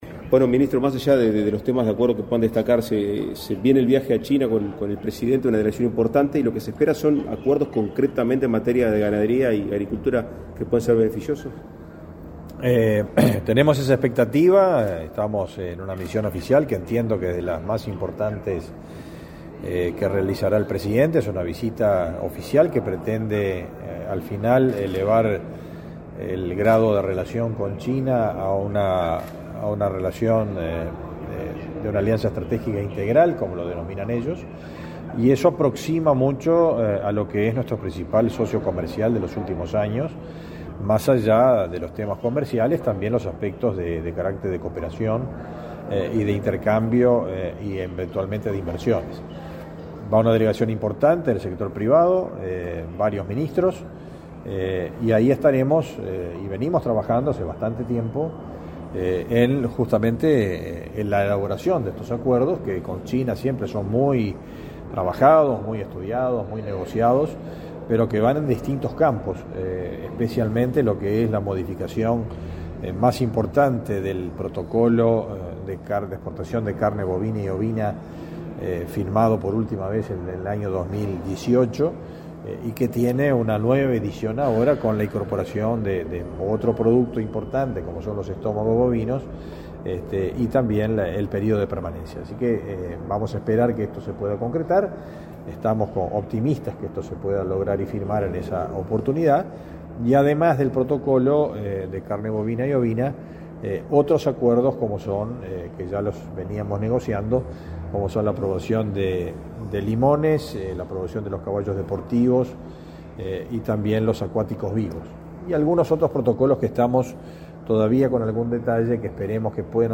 Declaraciones a la prensa del ministro de Ganadería, Agricultura y Pesca, Fernando Mattos